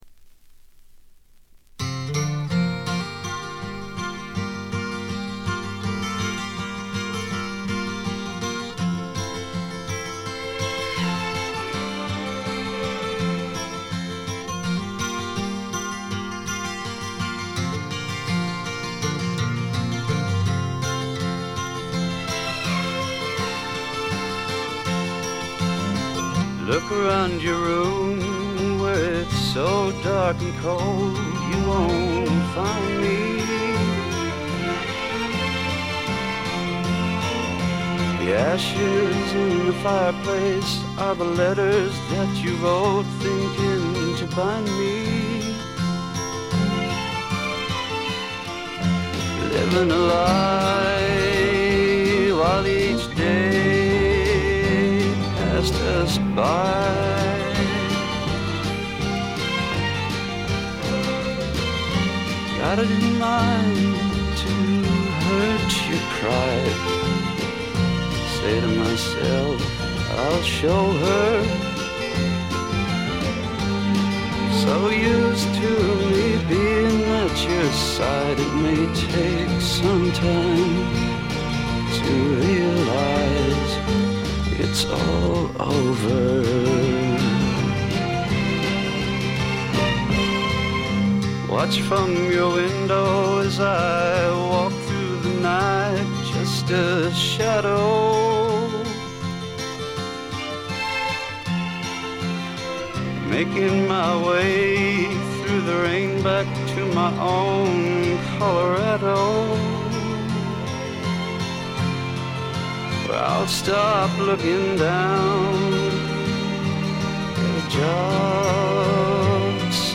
ところどころでチリプチ。
すべて自作曲で独特のヴォーカルもしっかりとした存在感があります。
試聴曲は現品からの取り込み音源です。